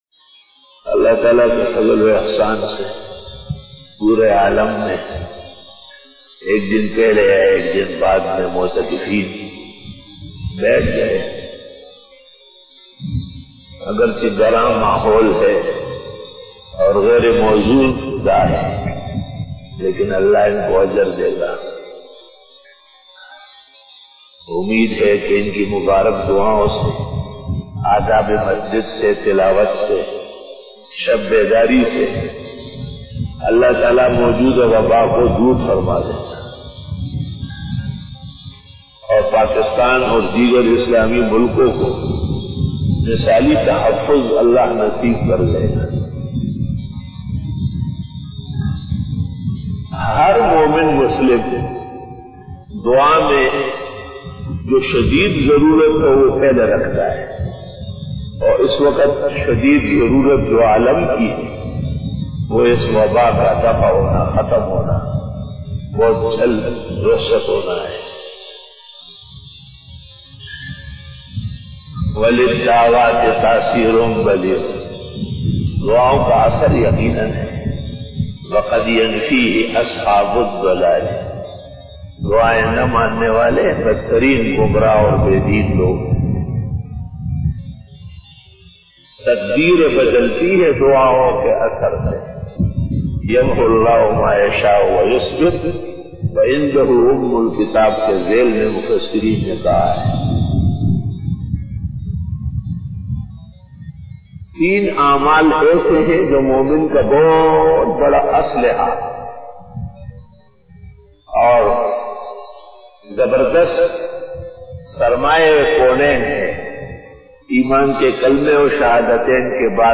After Fajar Byan